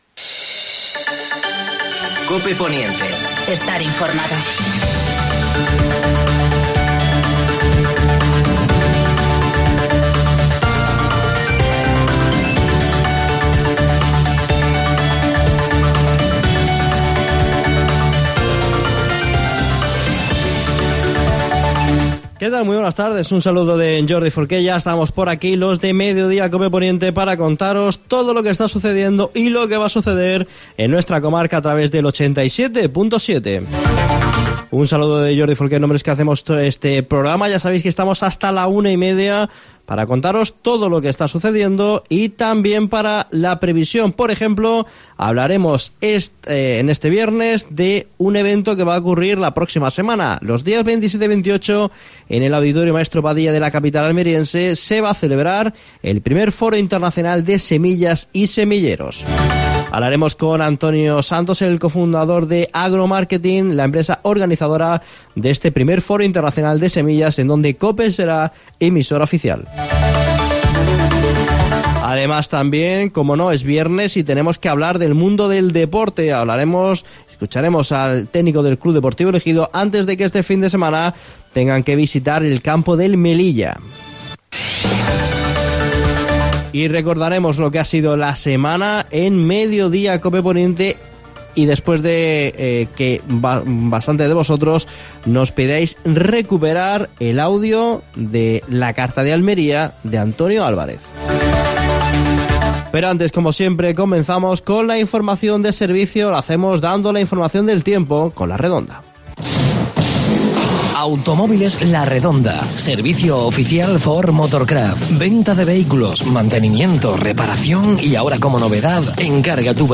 AUDIO: Actualidad en el Poniente. Entrevista